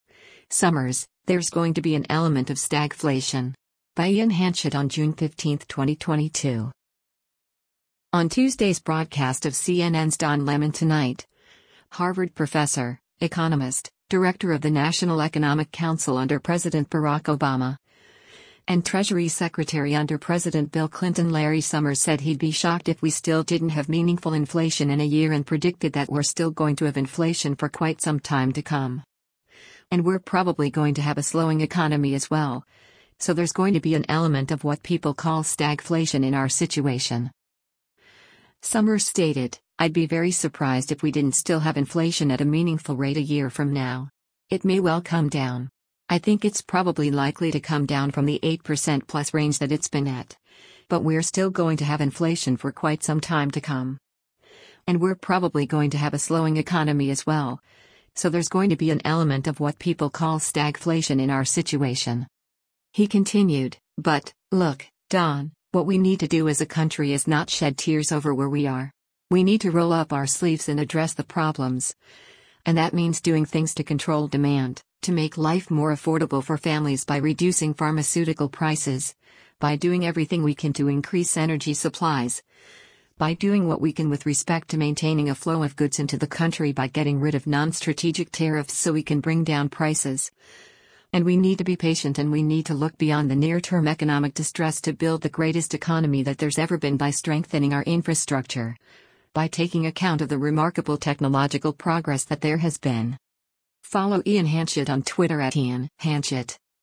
On Tuesday’s broadcast of CNN’s “Don Lemon Tonight,” Harvard Professor, economist, Director of the National Economic Council under President Barack Obama, and Treasury Secretary under President Bill Clinton Larry Summers said he’d be shocked if we still didn’t have meaningful inflation in a year and predicted that “we’re still going to have inflation for quite some time to come.